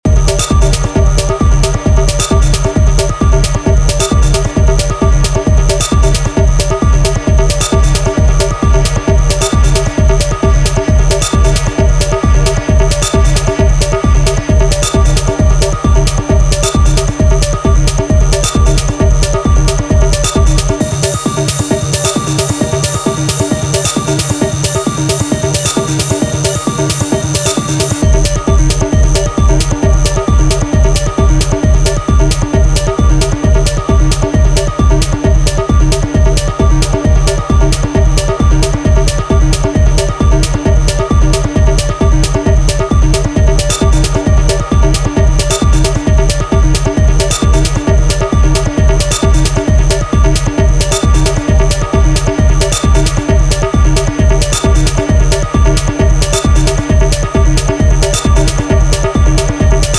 deep and intense